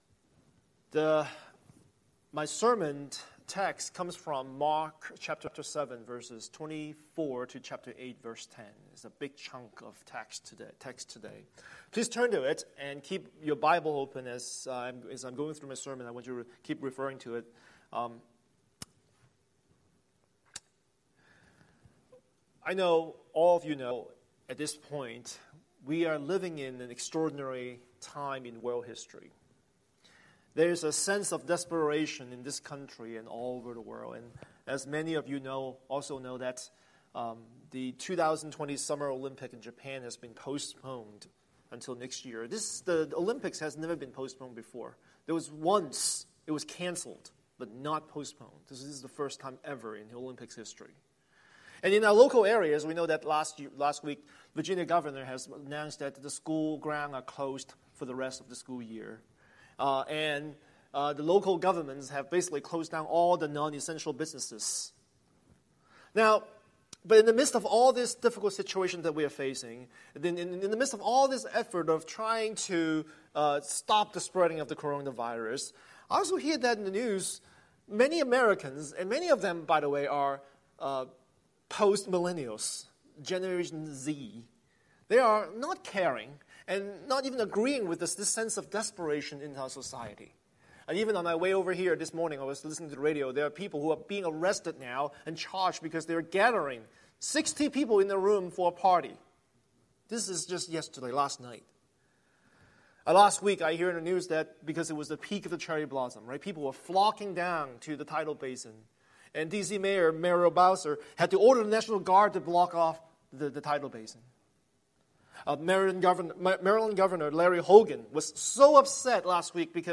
Scripture: Mark 7:24-8:10 Series: Sunday Sermon